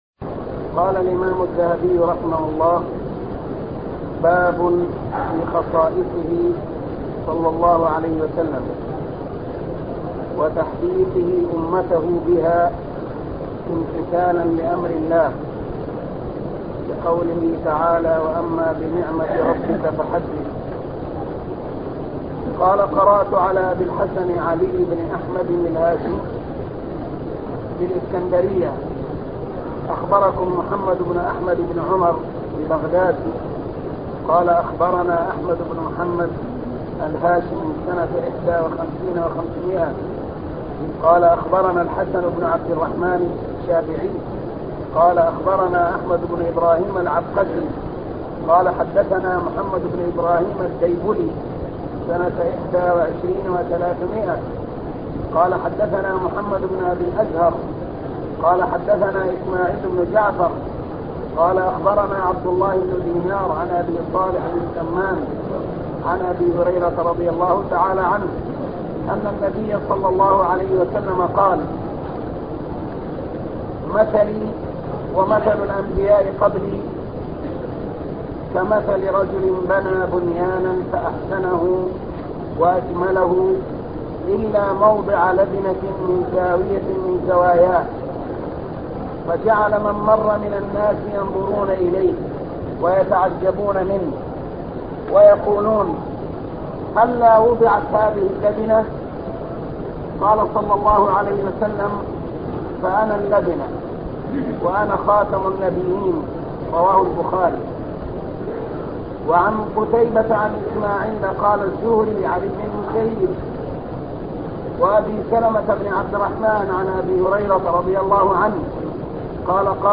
شرح السيرة النبوية الدرس 91